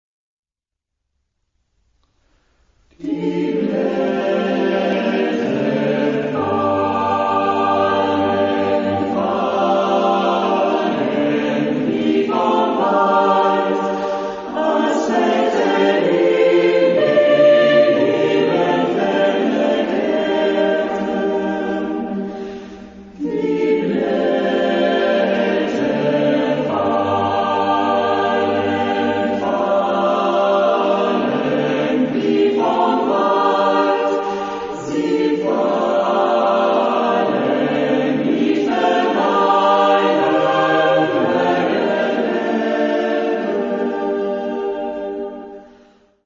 Genre-Style-Forme : Profane ; Poème
Caractère de la pièce : nostalgique
Type de choeur : SATB  (4 voix mixtes )
Tonalité : sol mineur